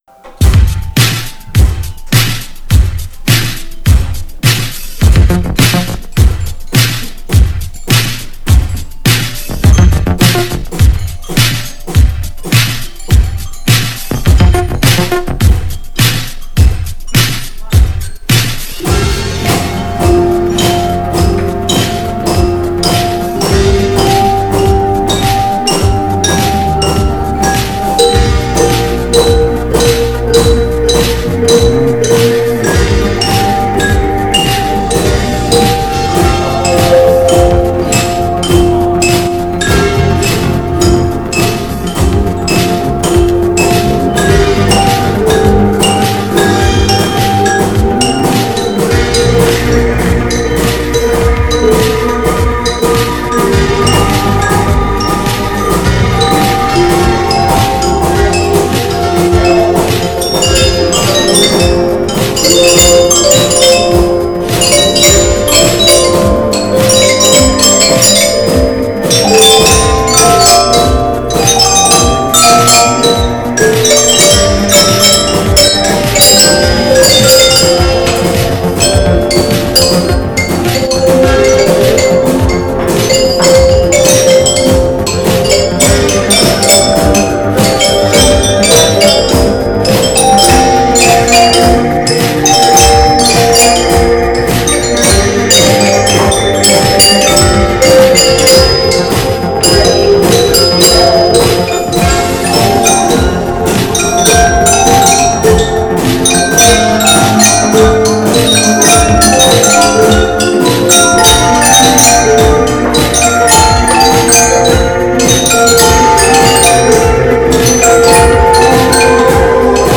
Year 8Y used glockenspeils and xylophones to create a rendition of Queen’s Radio Ga Ga: Listen here!